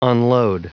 Prononciation du mot unload en anglais (fichier audio)
Prononciation du mot : unload